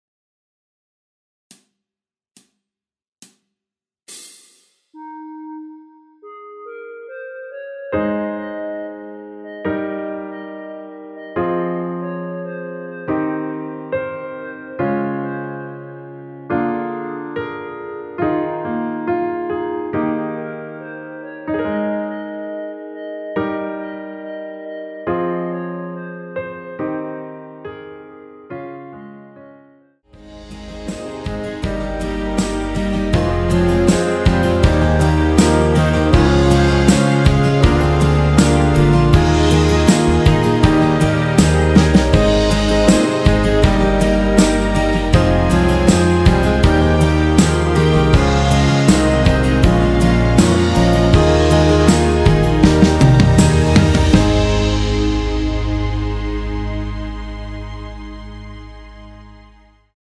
노래가 바로 시작하는곡이라 카운트 넣어 놓았으며
그리고 엔딩이 너무 길고 페이드 아웃이라 라랄라 반복 2번으로 하고 엔딩을 만들었습니다.
원키에서(-1)내린 멜로디 포함된 MR입니다.
Ab
앞부분30초, 뒷부분30초씩 편집해서 올려 드리고 있습니다.